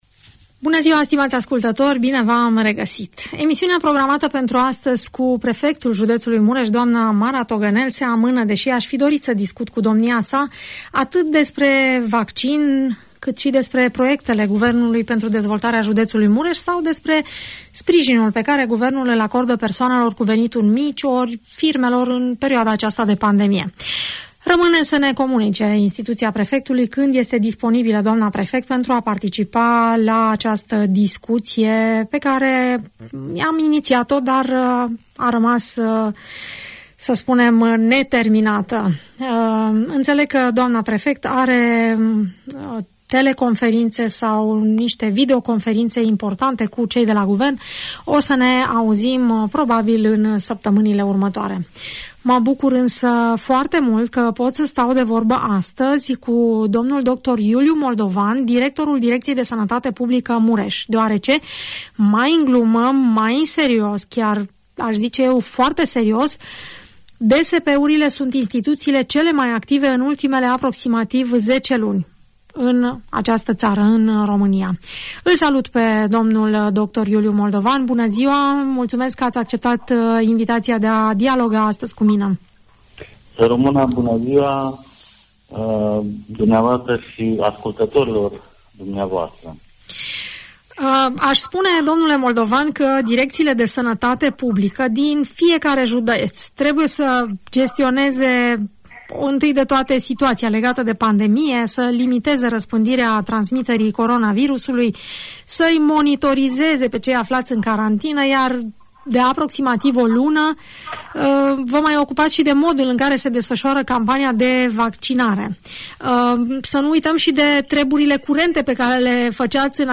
Directorul Direcției de Sănătate Publică Mureș, dl dr. Iuliu Moldovan, vorbește la Radio Tg. Mureș despre cele mai importante aspecte ale celei de-a doua etape de vaccinare.